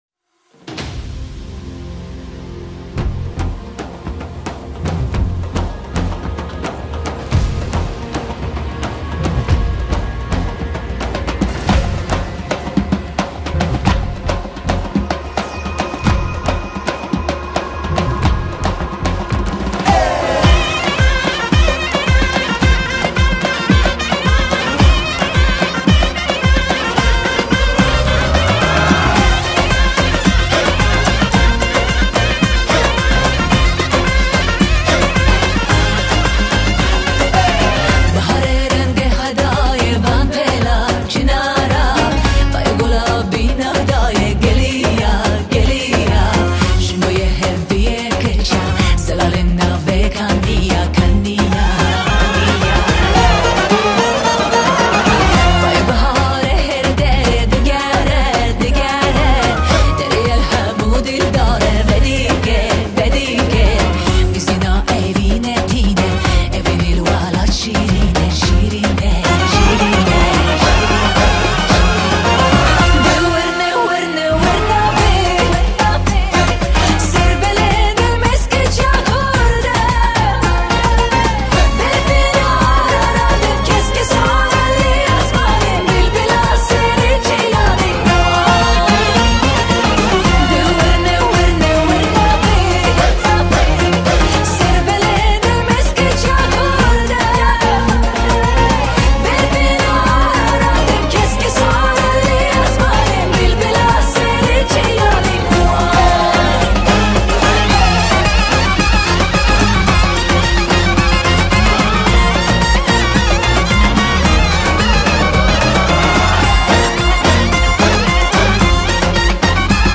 Kurdish folk music